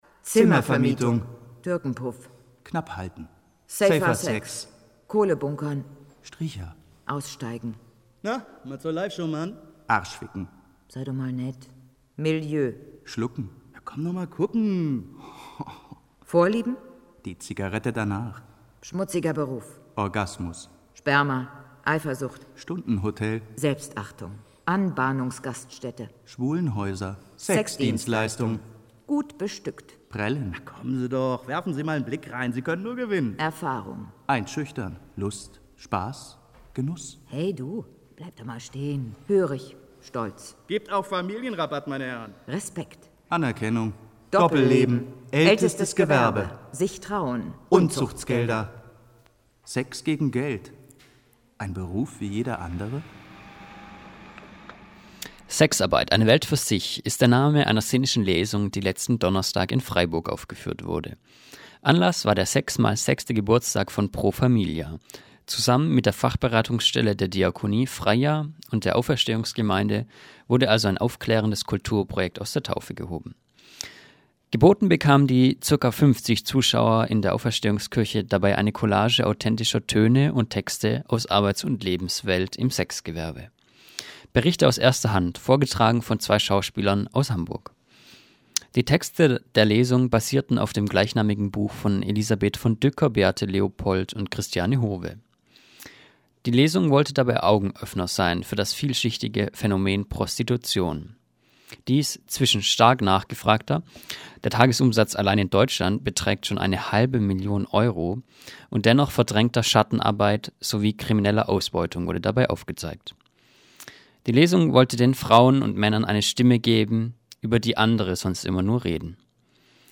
Sexarbeit - eine Welt für sich. Das ist der Name einer Szenischen Lesung, die letzten Donnerstag in Freiburg aufgeführt wurde.